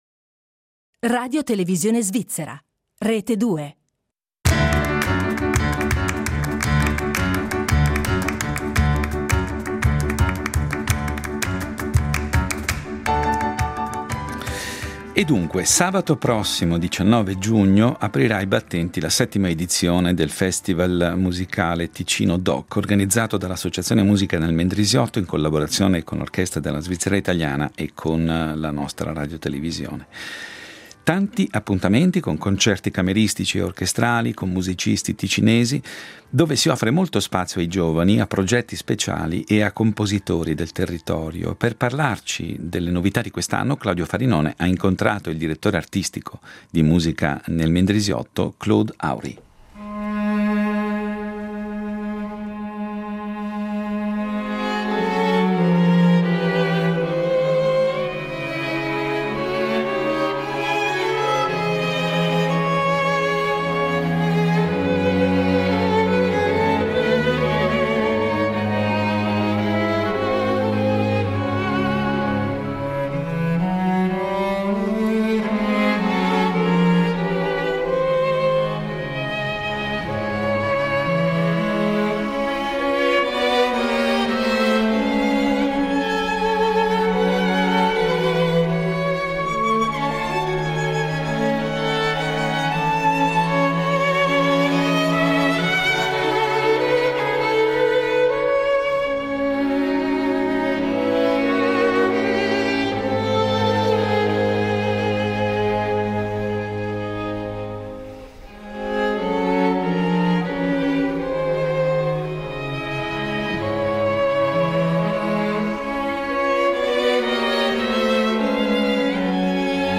Ticino Doc: incontro